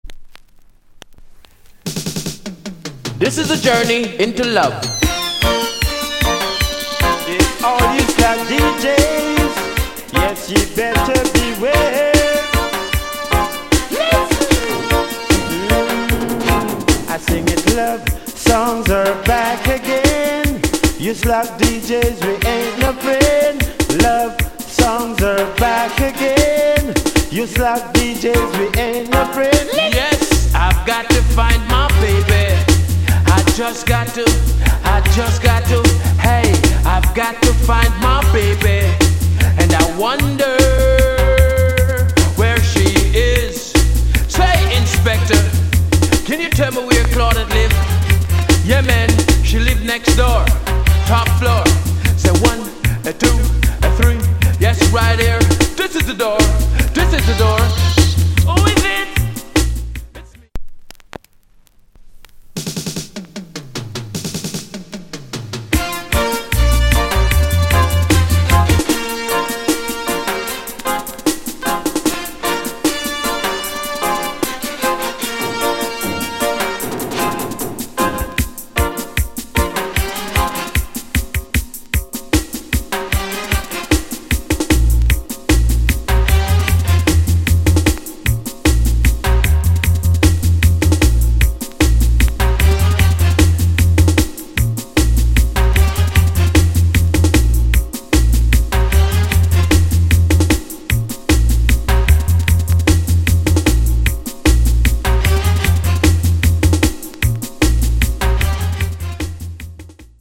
Late 80's
Wicked Singer & DJ Tune!! shock out 同トラ置換テキスト